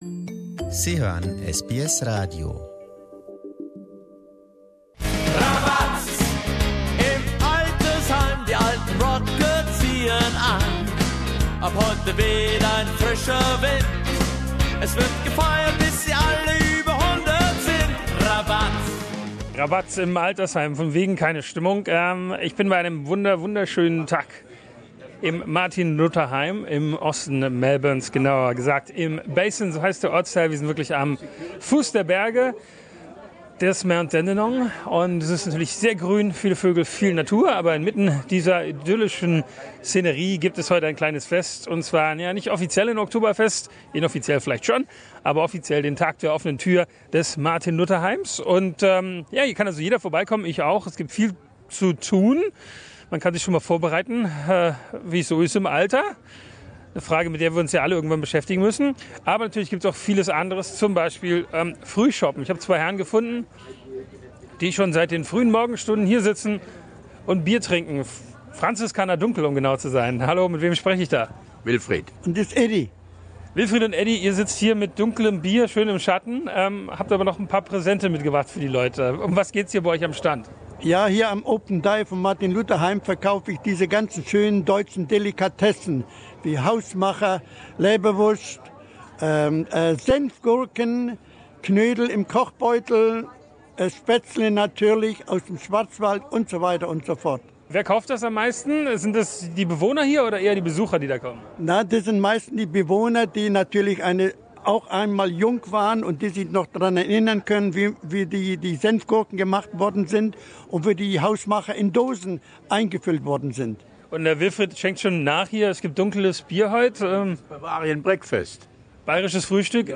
Auch in Australien altert die deutsche Bevölkerung stetig. Deswegen erfreuen sich deutsche Altersheime größter Beliebtheit. Grund genug mit dem Mikro einmal vorbeizuschauen.